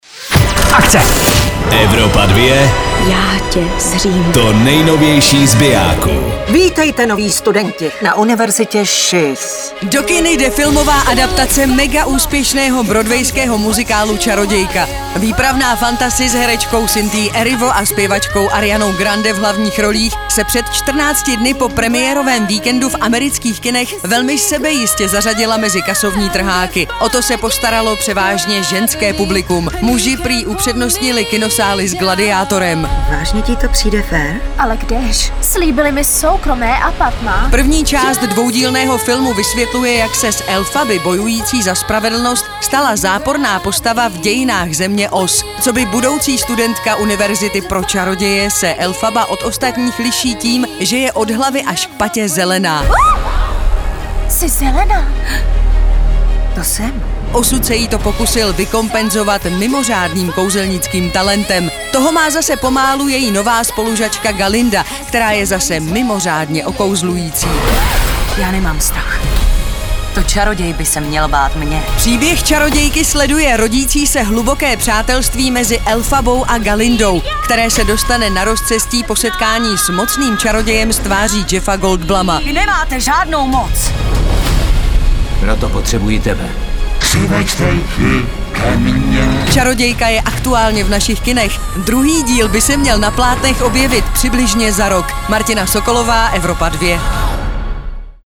filmový trailer